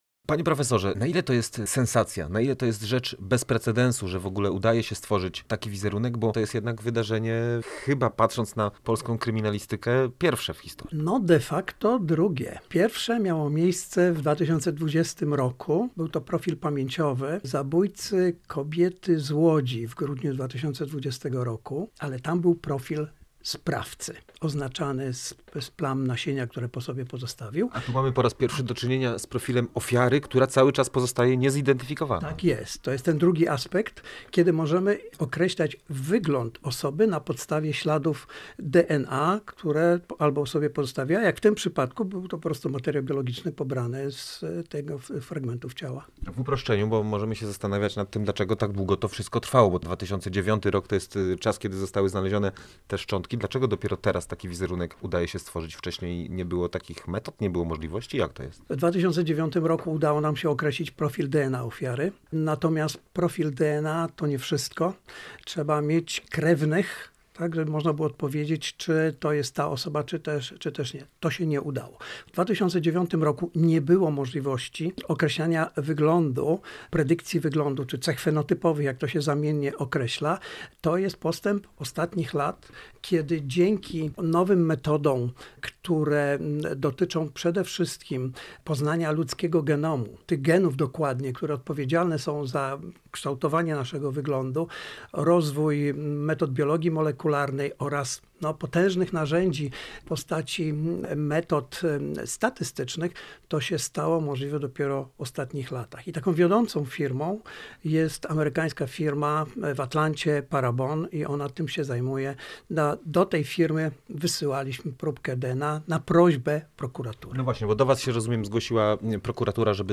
Przełom w sprawie sprzed lat i potęga DNA. Rozmowa ze specjalistą w zakresie genetyki sądowej